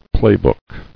[play·book]